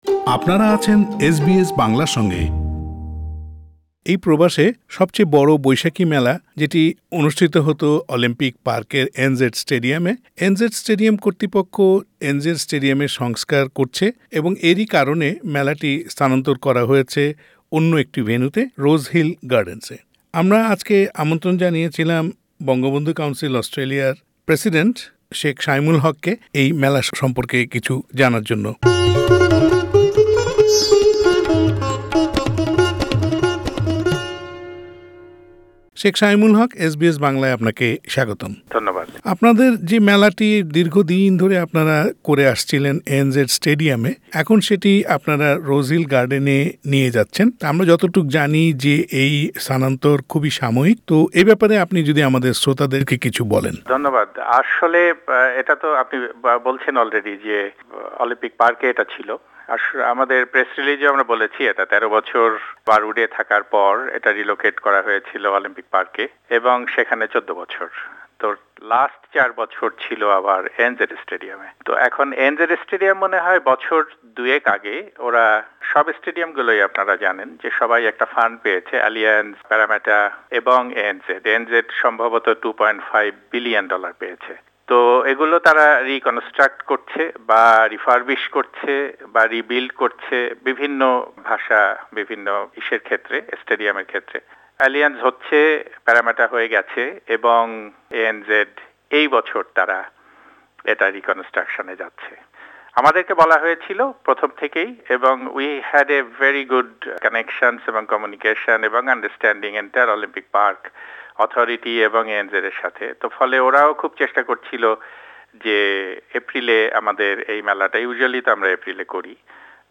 Bangla New Year Festival : Interview